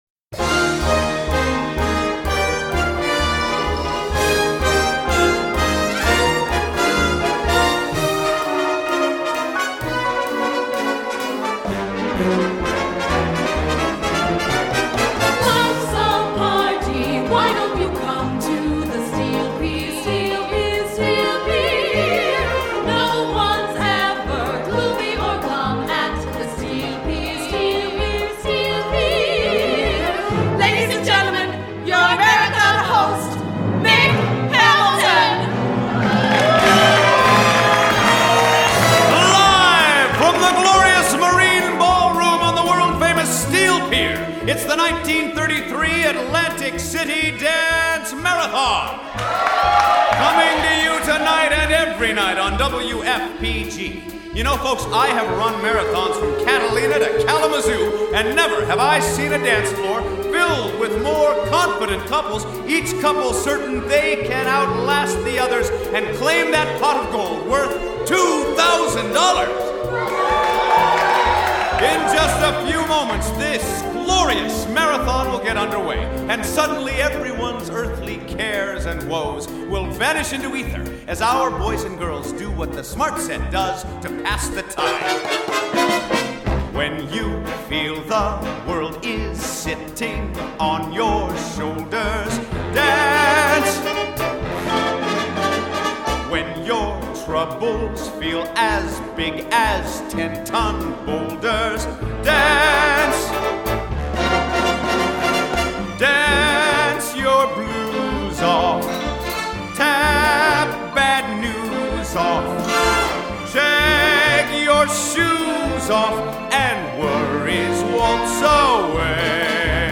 Original Broadway Cast